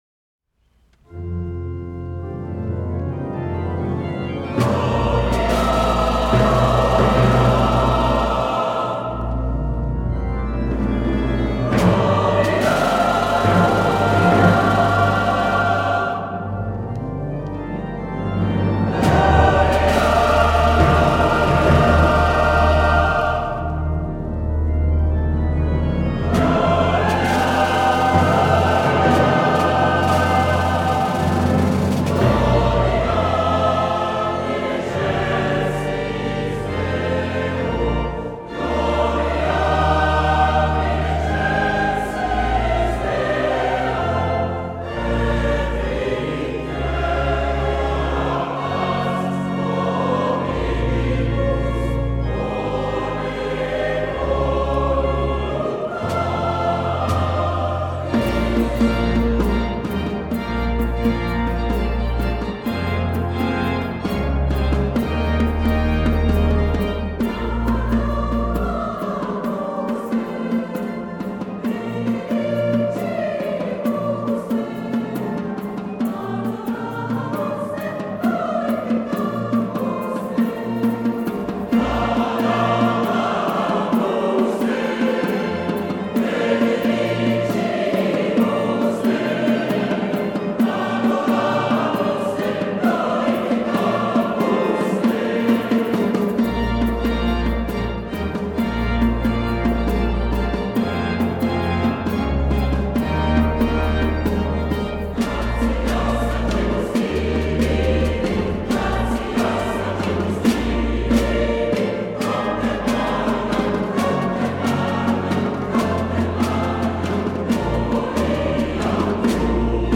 Mars 2018 Vidéo invitation au concert des Voix de la Montagne de mai 2018  Extrait de la répétition du 28 mars 2018 des Voix de la Montagne  Une Petite Musique de Mozart arrangement vocal du premier mouvement de la suite du même nom.